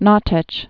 (nôtĕch)